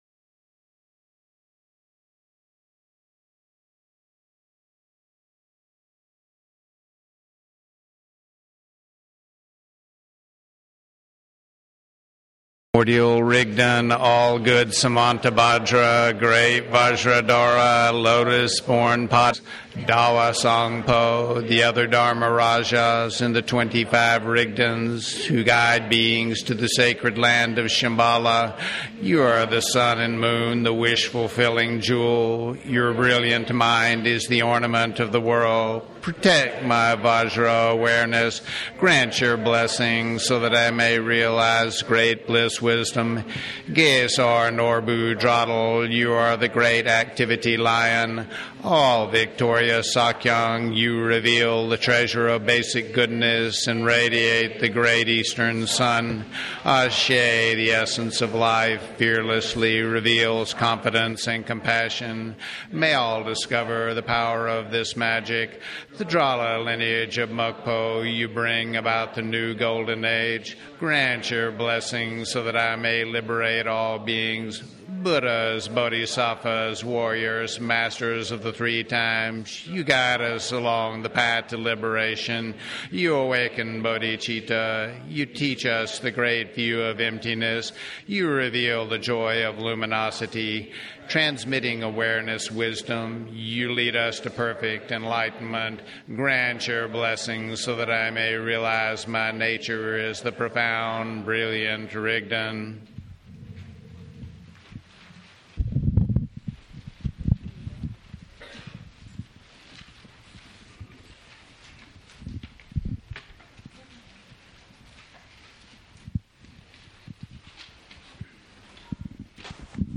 Download Listen to the Sakyong’s Public Talk